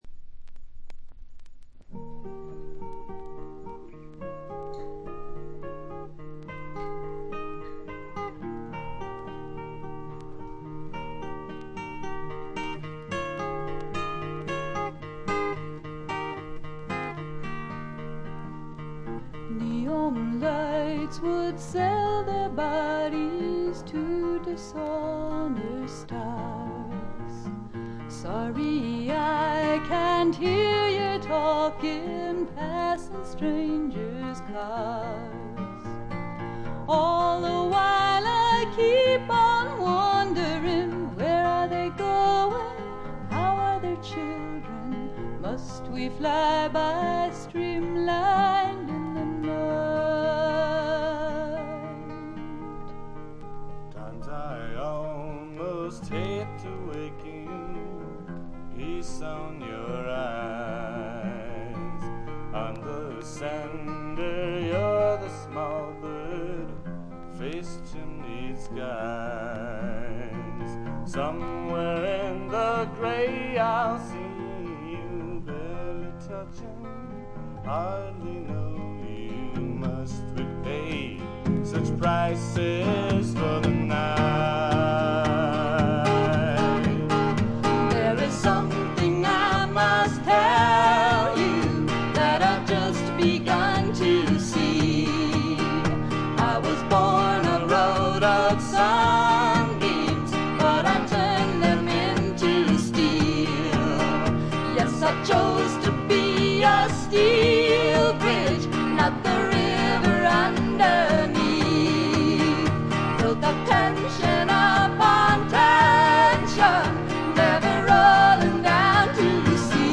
細かなチリプチのみ。
試聴曲は現品からの取り込み音源です。